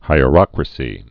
(hīə-rŏkrə-sē, hī-rŏk-)